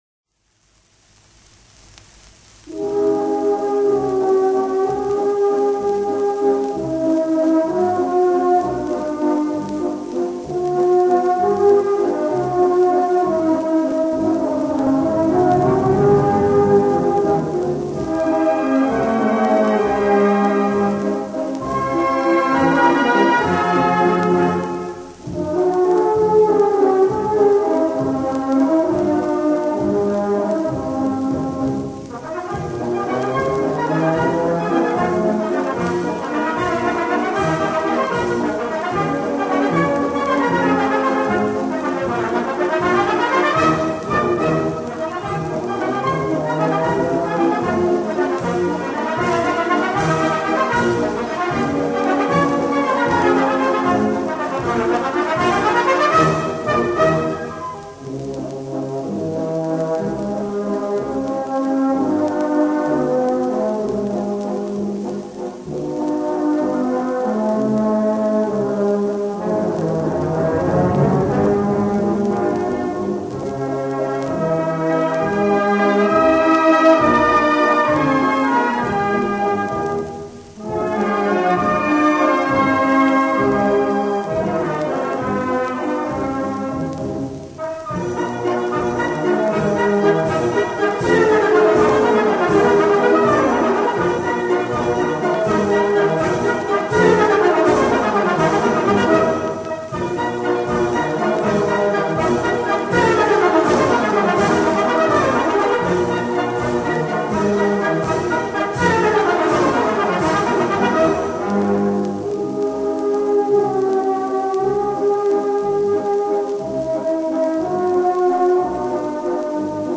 Как играют трубы и баритоны!!!!!!!!!!!!